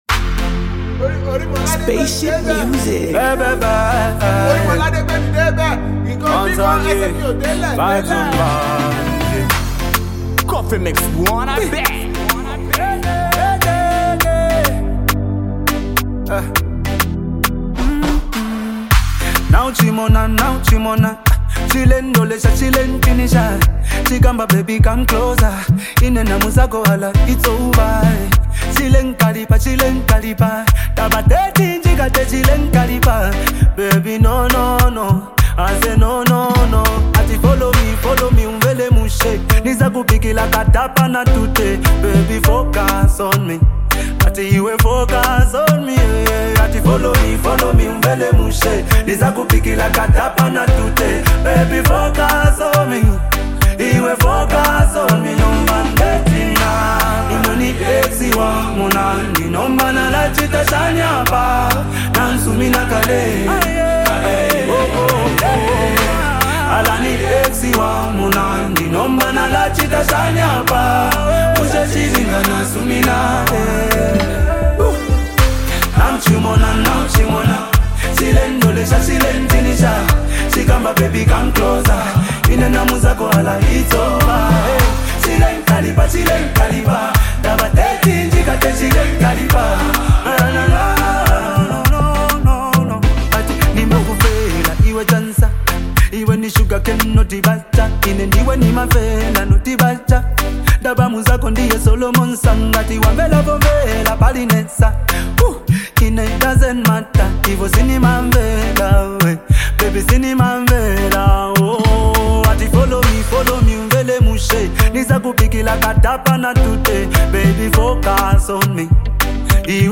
fusing Afro-pop rhythms with soulful harmonies.